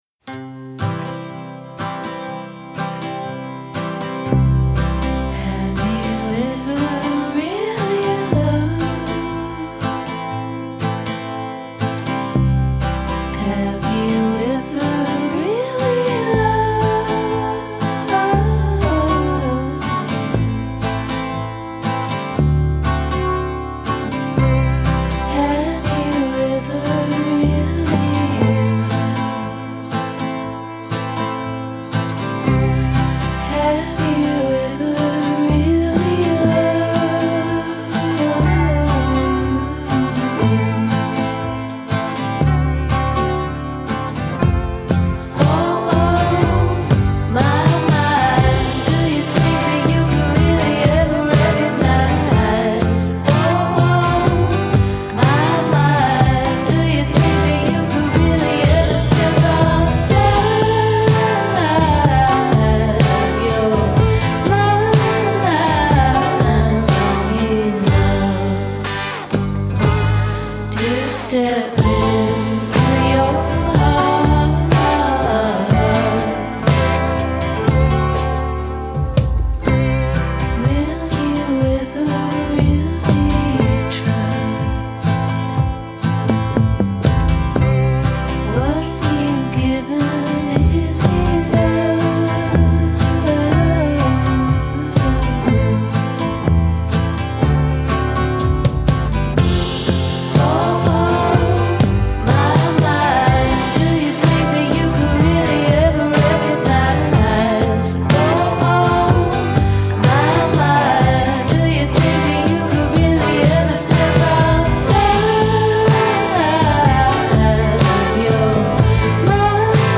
Harrisonian pensiveness
has a stately, Harrisonian pensiveness about it